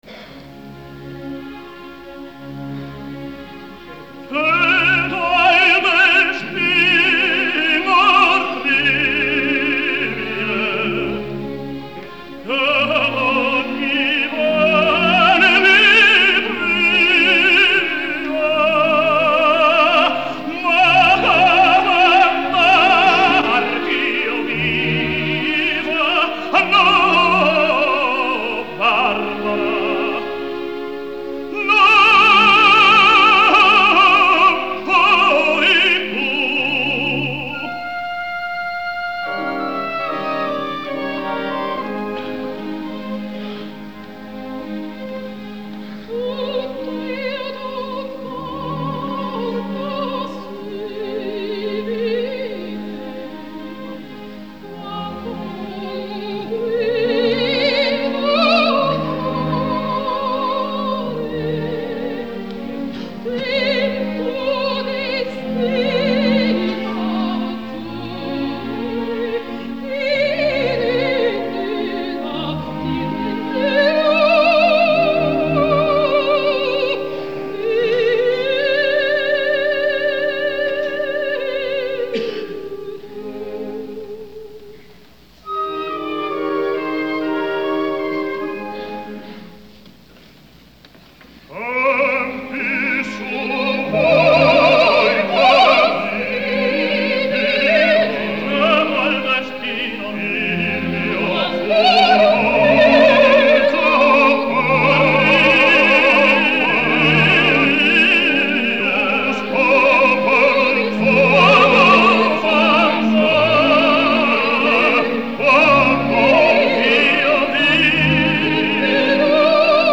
Запись: 1959 г. (live).
Итальянский певец (тенор).
Американская певица, греческого происхождения (сопрано).